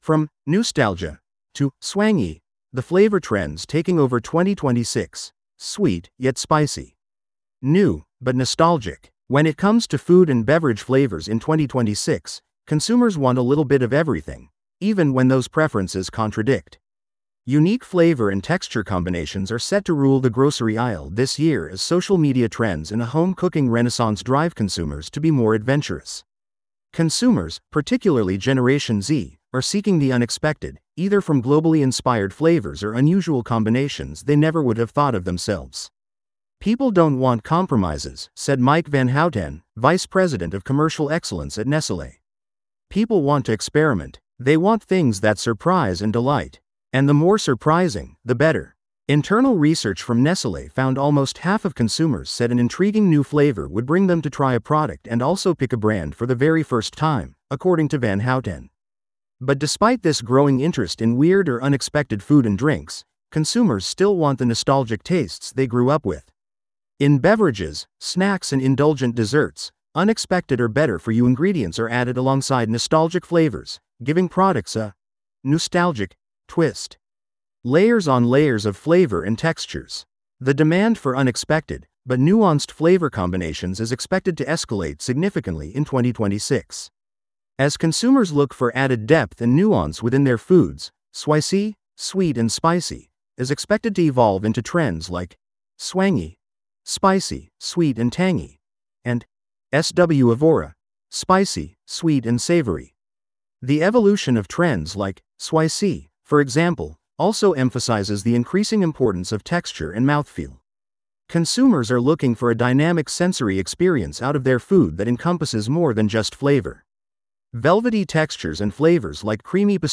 This audio is generated automatically.